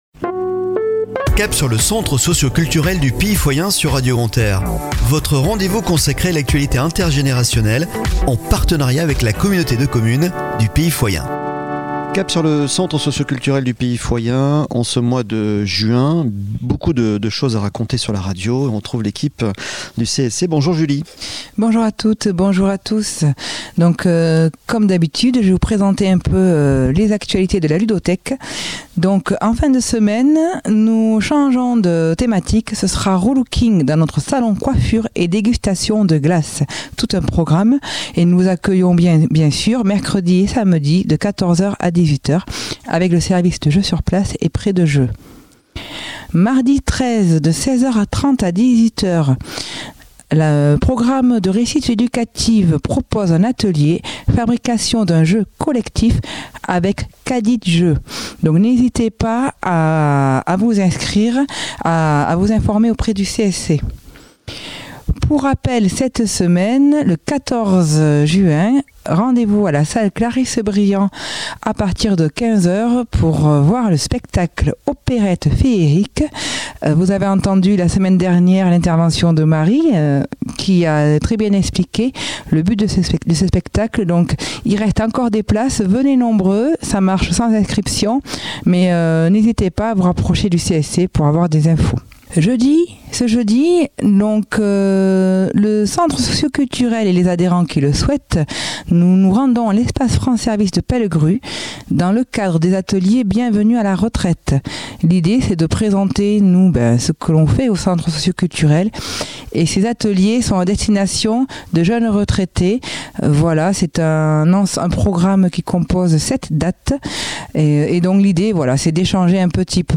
Chronique de la semaine du 12 au 18 Juin 2023 !